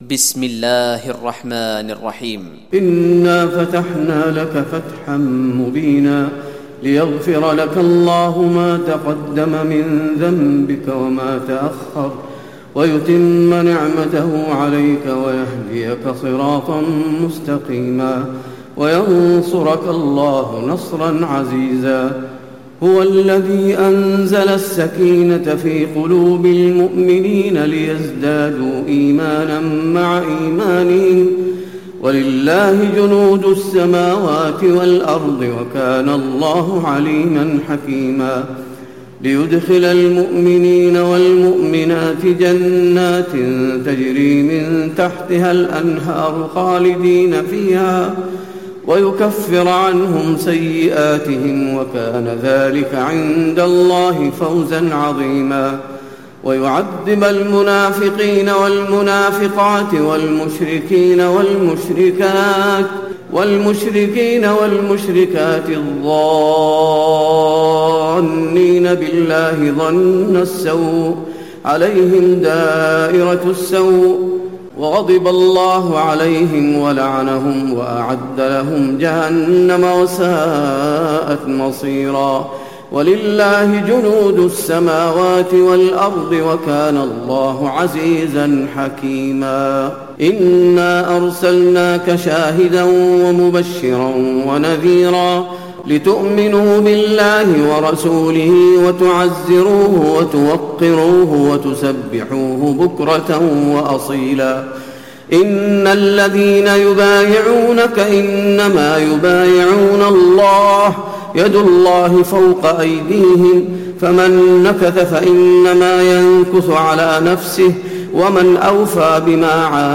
تراويح ليلة 25 رمضان 1436هـ سورة الفتح كاملة Taraweeh 25 st night Ramadan 1436H from Surah Al-Fath > تراويح الحرم النبوي عام 1436 🕌 > التراويح - تلاوات الحرمين